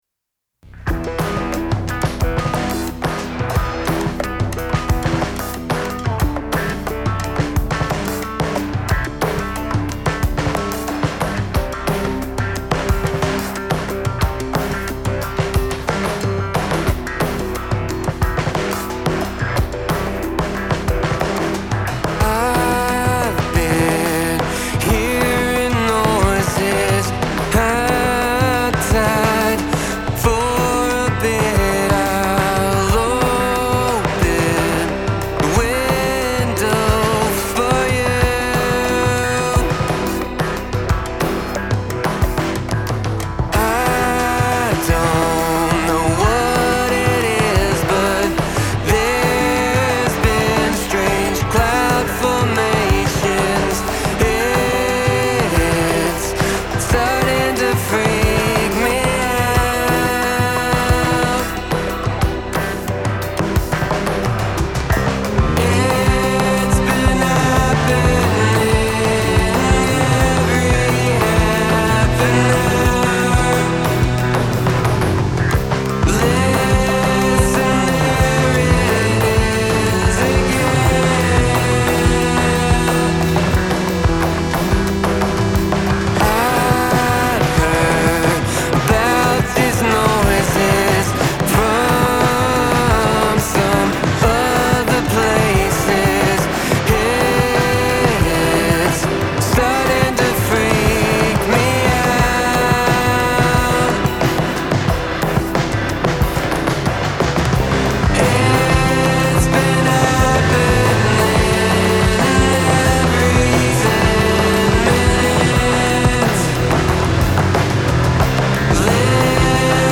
alt rock band
a high energy, rhythm driven track